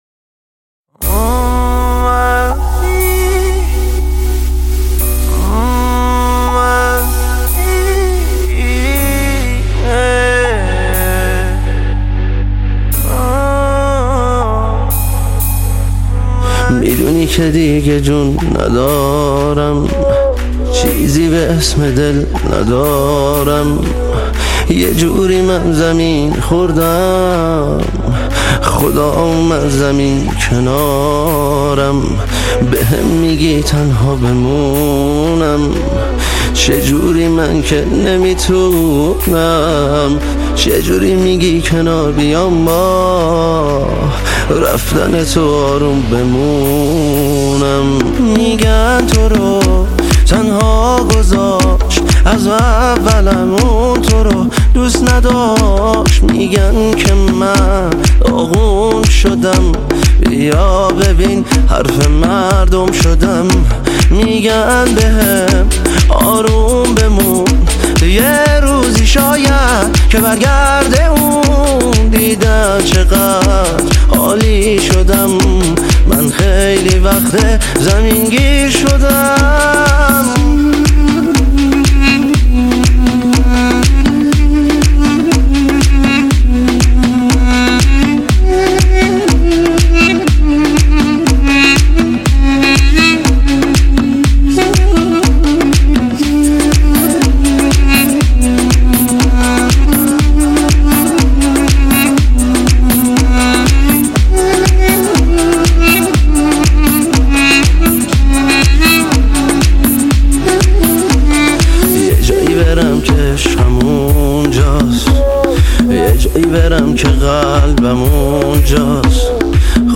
دودوک
تک اهنگ ایرانی